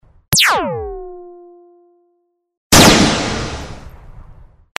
Звуки бластера
Выстрелы лазерного пистолета